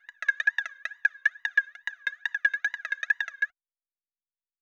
SqueakyExplanation.wav